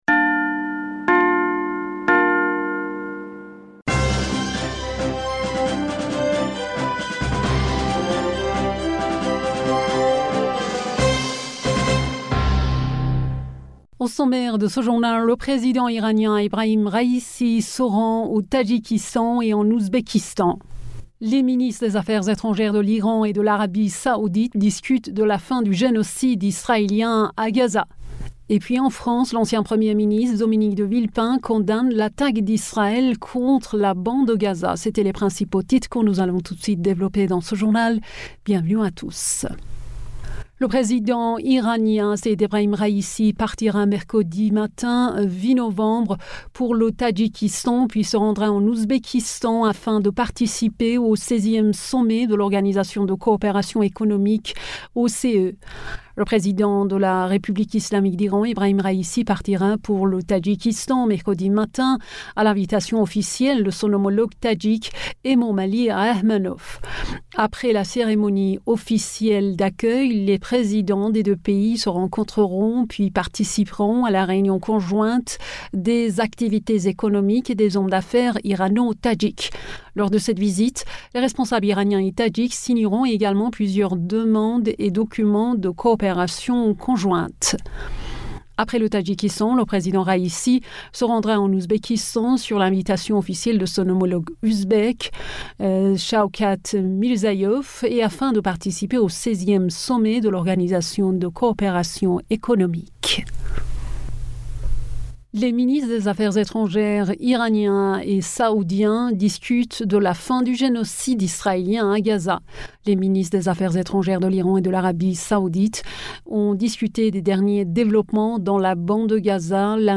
Bulletin d'information du 07 Novembre 2023